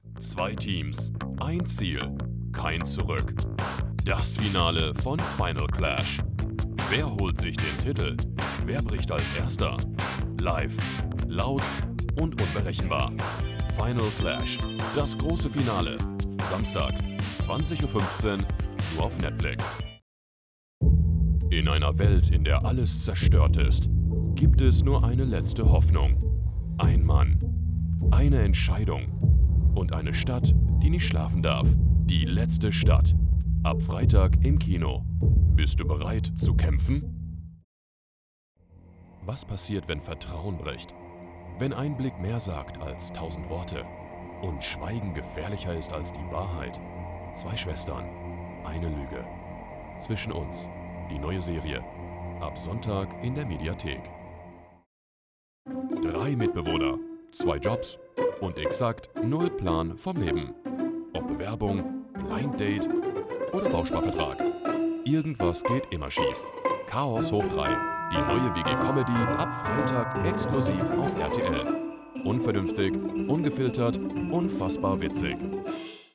Voice Over Demos
Commercials
High-impact reads for commercial success